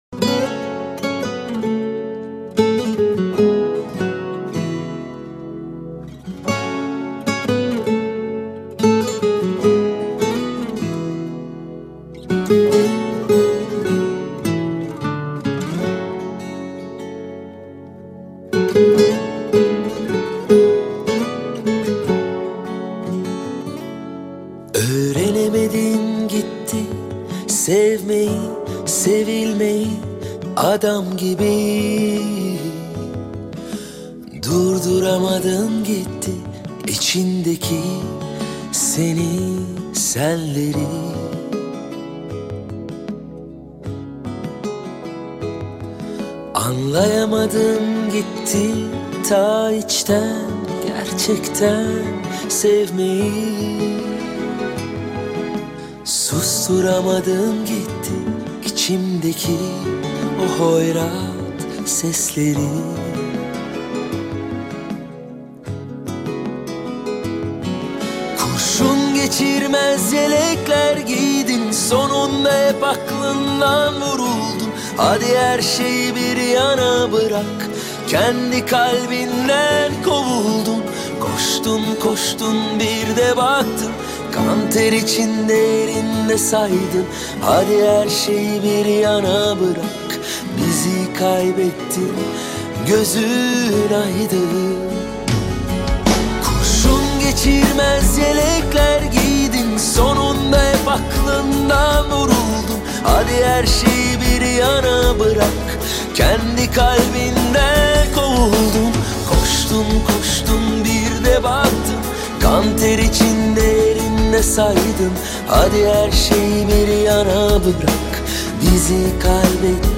Категория: Восточная музыка » Турецкие песни